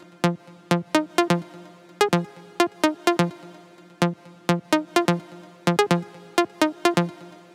VDE 127BPM Rebound Melo Root F.wav